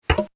Kerplunk.mp3